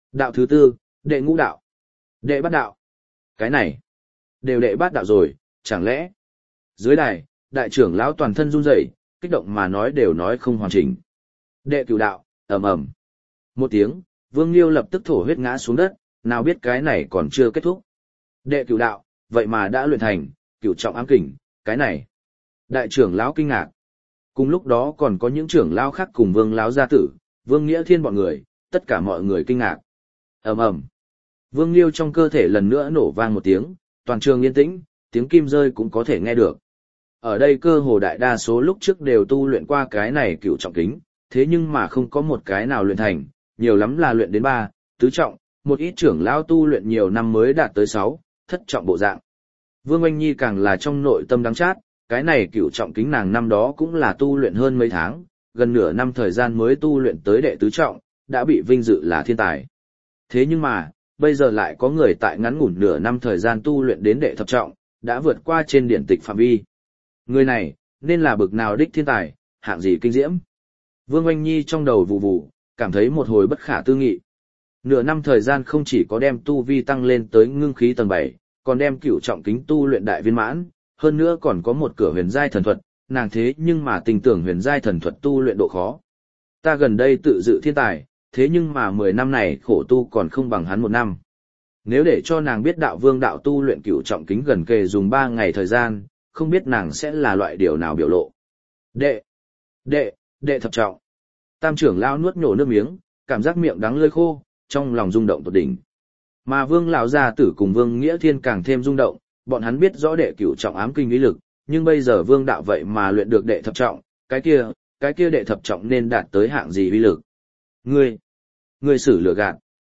Thiên Thần Chúa Tể Audio - Nghe đọc Truyện Audio Online Hay Trên TH AUDIO TRUYỆN FULL